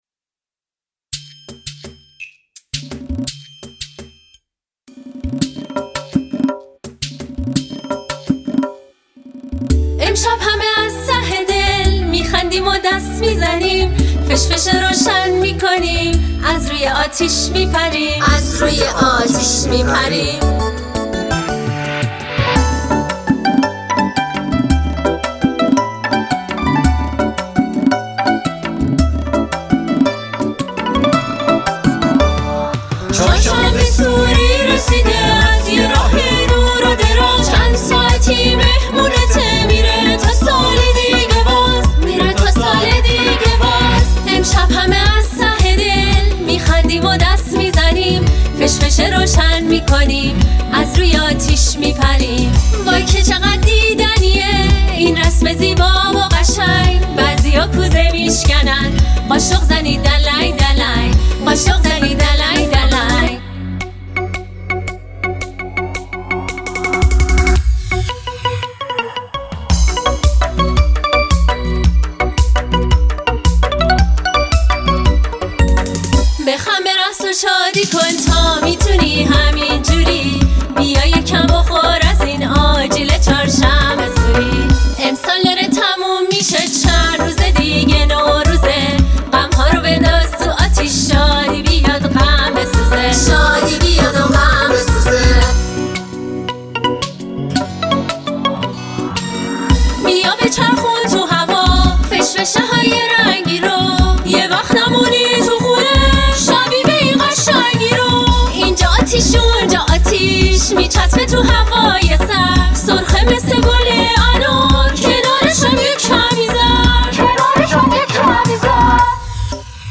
ترانه کودکانه